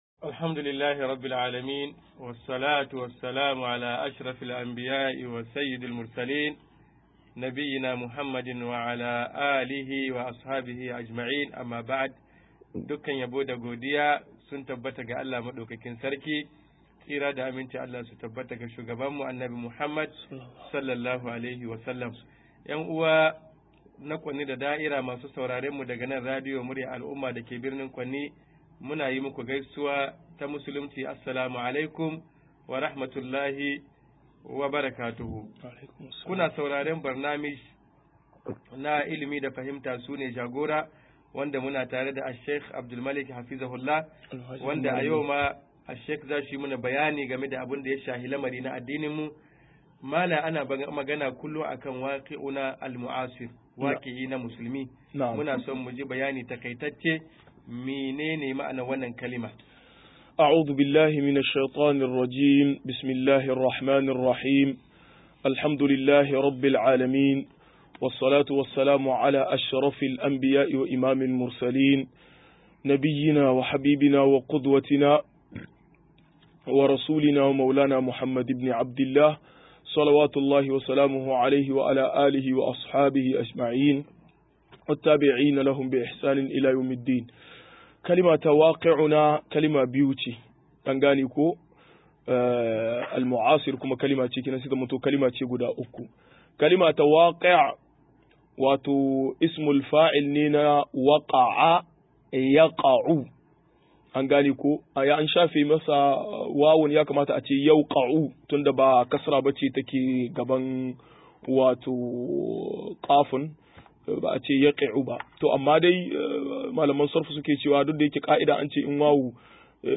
157-Hakim Musulmi a Yau 1 - MUHADARA